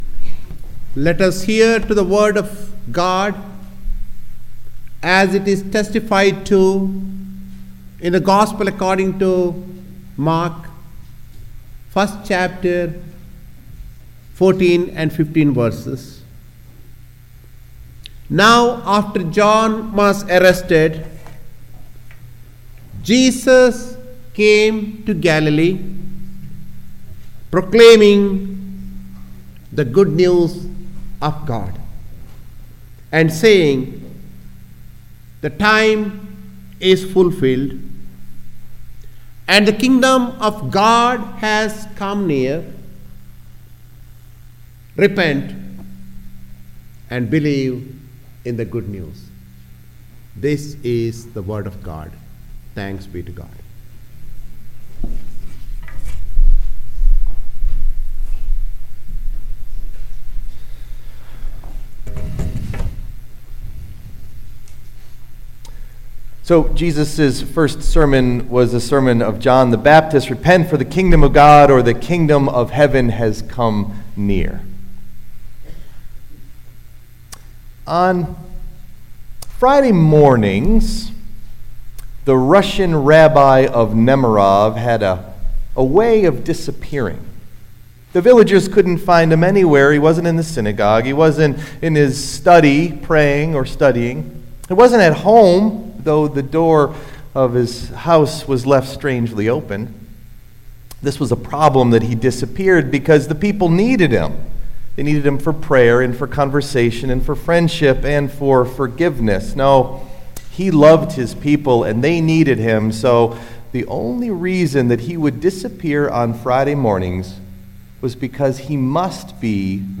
Message Delivered at: The United Church of Underhill (UCC and UMC)
Can heaven be found? (Our Scripture passage was read by Bishop Devadhar of the New England Annual Conference, United Methodist Church.)